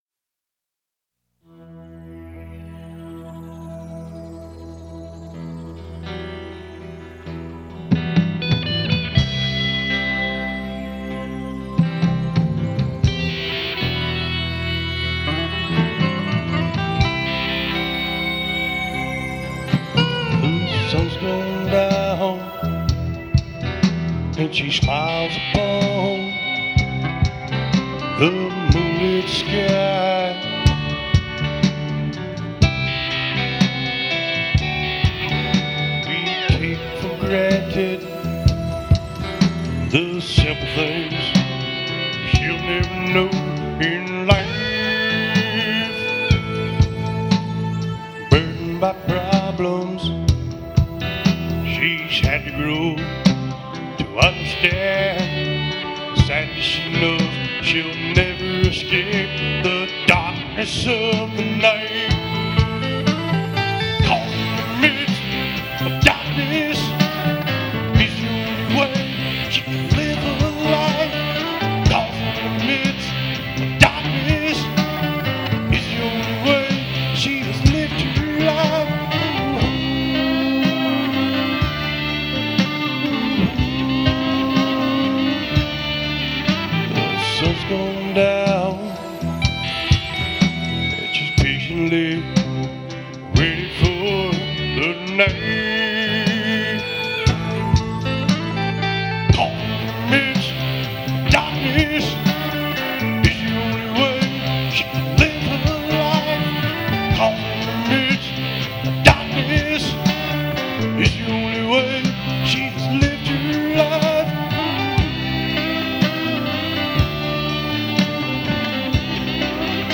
Guitar&Music
I am a Singer/Songwriter/Guitarist, and *WebMaster*.